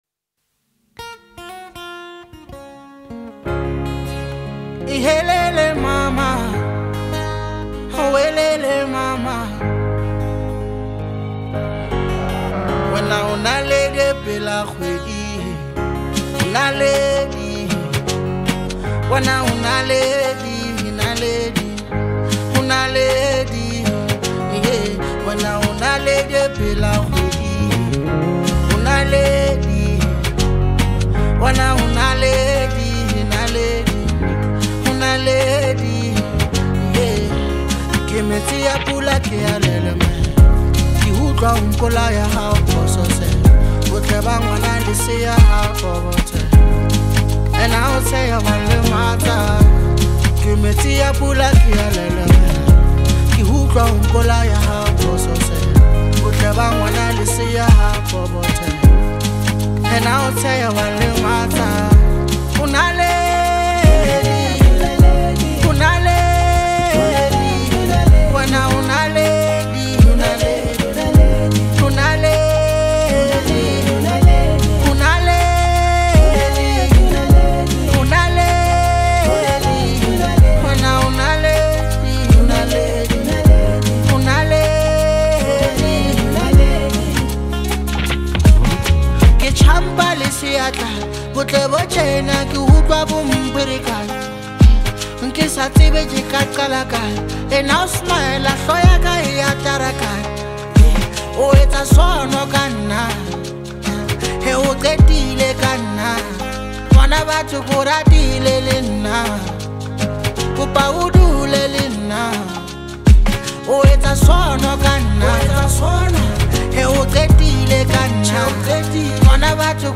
” which is a collection of seven incredible Hip Hop tracks.
It has got everything from catchy beats to heartfelt lyrics.